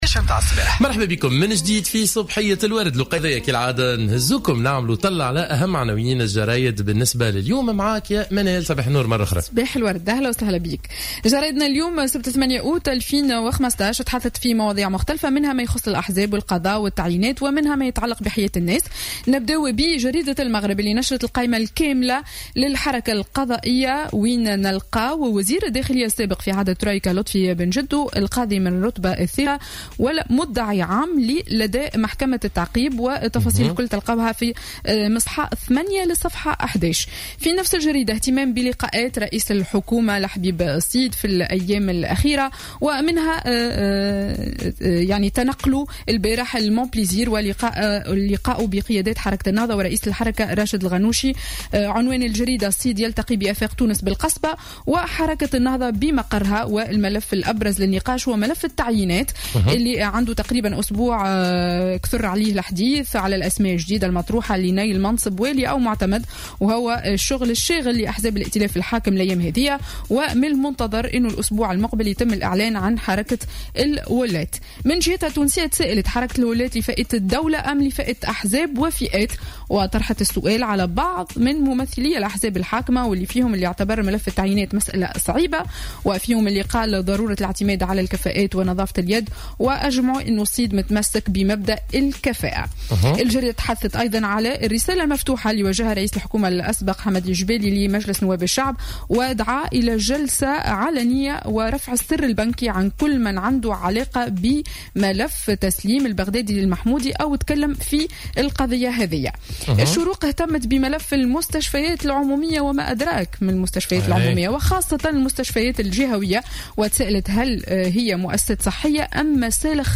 معرض الصحافة ليوم السبت 8 أوت 2015